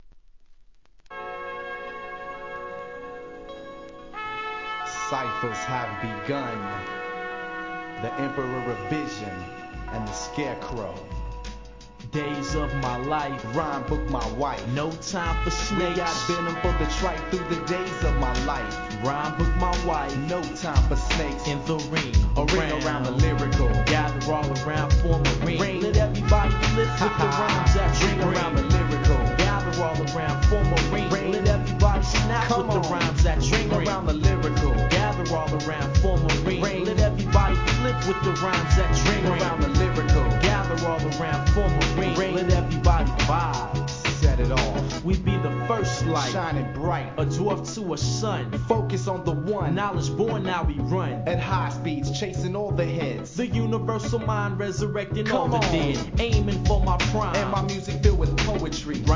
1. HIP HOP/R&B
スピリチュアルな極上ホーンが印象的なJAZZY HIP HOP!!